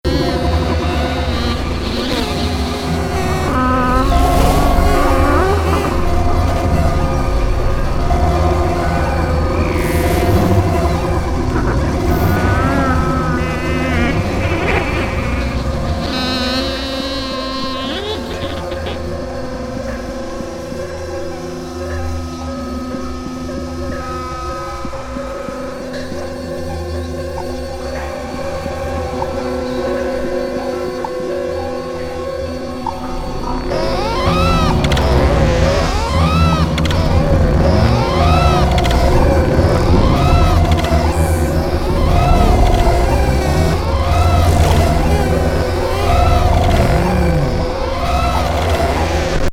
音～ノイズ～インダストリアルの世界。